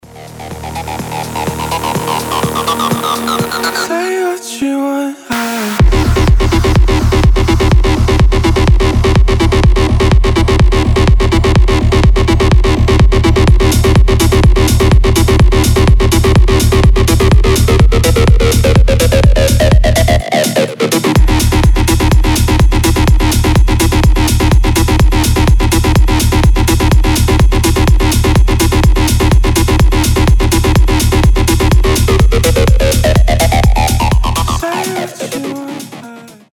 • Качество: 320, Stereo
Техно